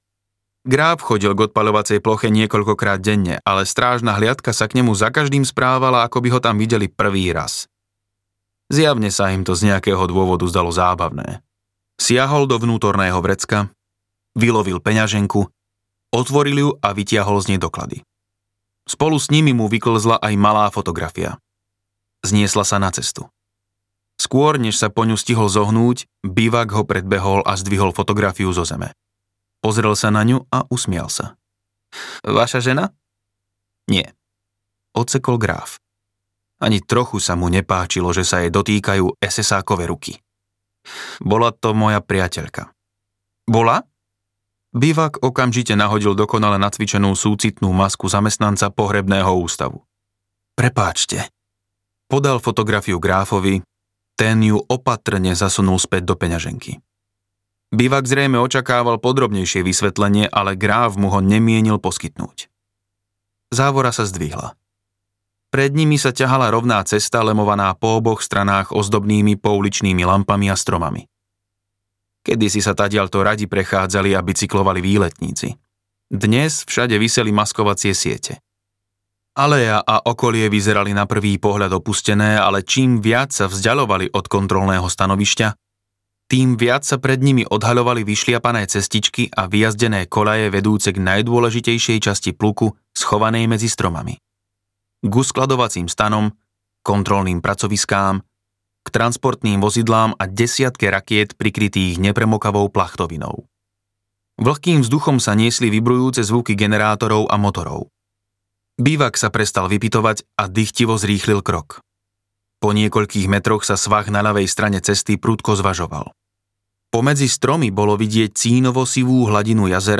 V2 audiokniha
Ukázka z knihy